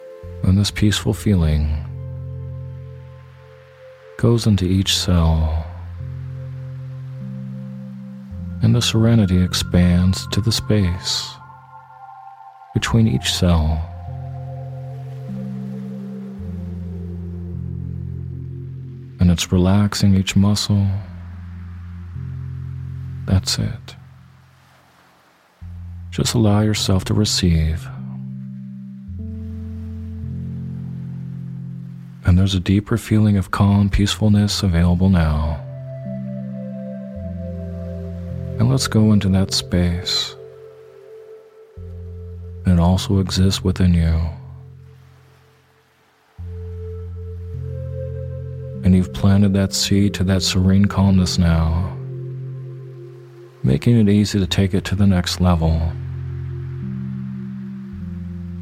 Sleep Hypnosis For Peacefulness In a Chaotic World